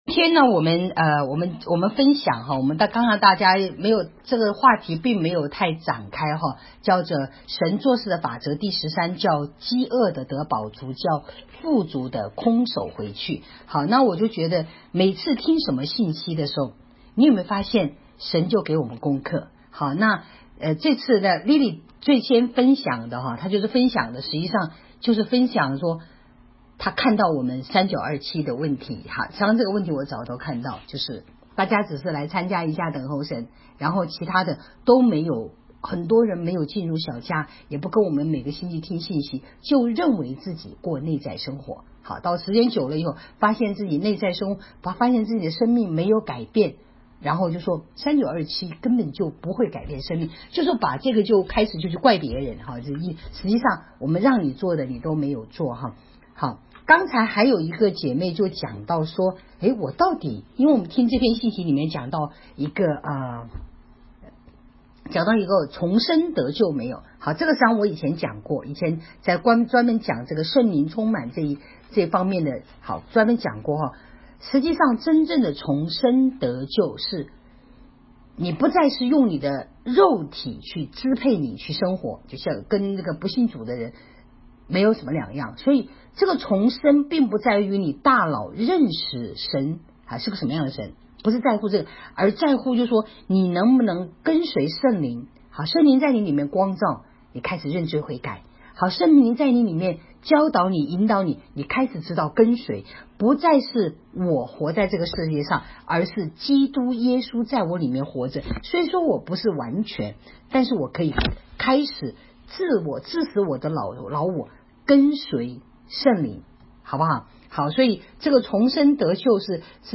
请点击连接，听聚会的录音。